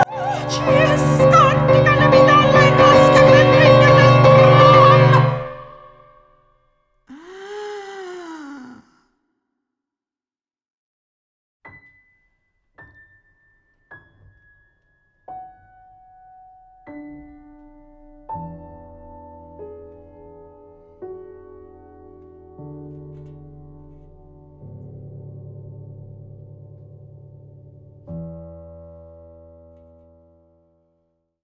per voce e pianoforte
per voce femminile e pianoforte